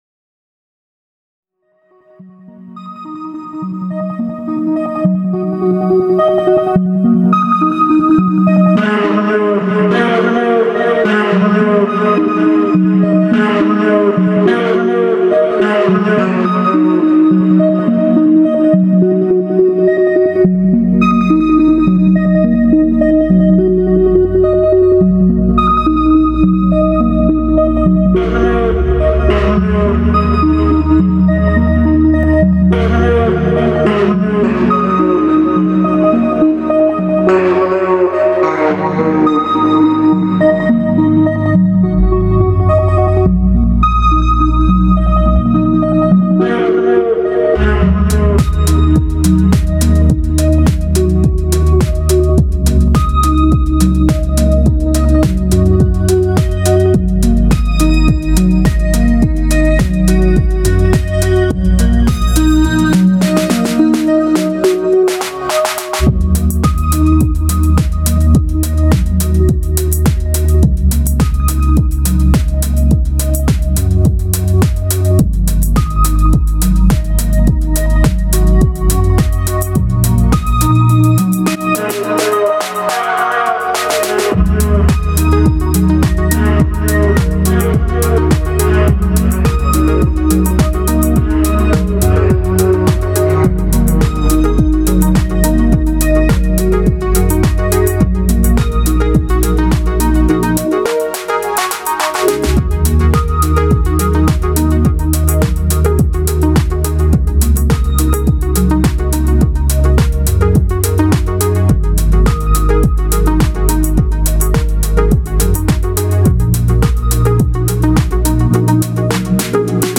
Minifreak and DT as usual…